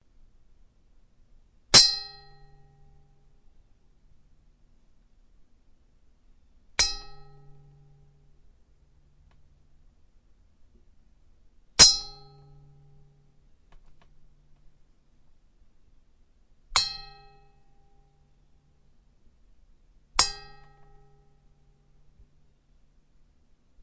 Bell Spittoon Metal Target Being Hit Sound Effect Free High Quality Sound FX
描述：贝尔痰盂金属目标被击中声音效果免费高品质声音效果